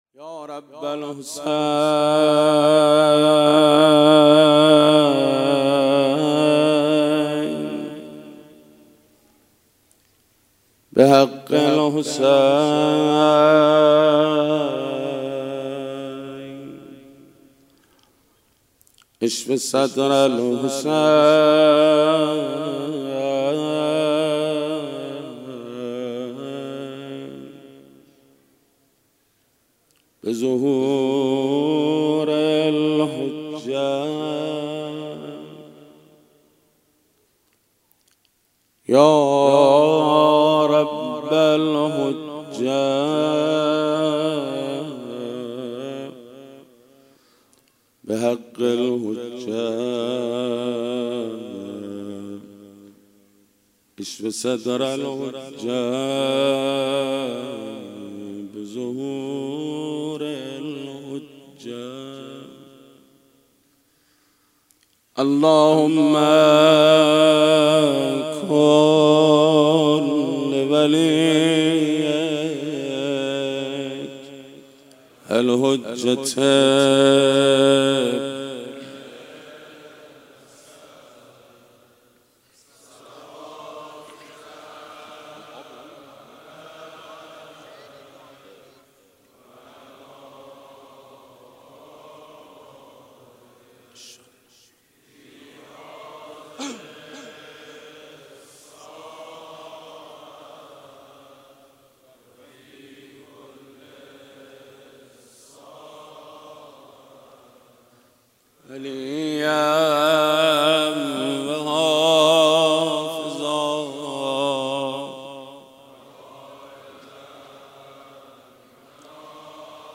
صوت مداحی محمود کریمی در شب چهارم مراسم فاطمیه در مسجد الهادی منتشر شد.
به گزارش خبرنگار فرهنگی باشگاه خبرنگاران پویا چهارمین جلسه عزاداری بمناسبت شهادت حضرت صدیقه طاهره (س) در هیات ثارالله(ع) مسجد الهادی (شرق تهران) برگزار شد.
روضه زمینه واحد شور انتهای‌پیام/